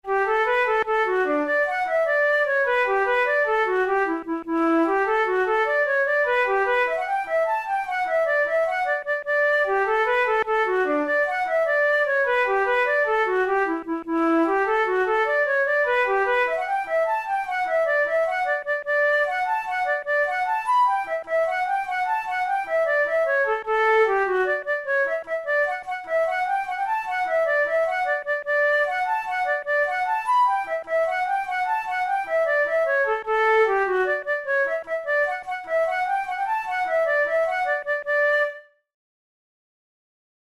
InstrumentationFlute solo
KeyD major
RangeD4–B5
Time signature6/8
Tempo100 BPM
Jigs, Traditional/Folk
Traditional Irish jig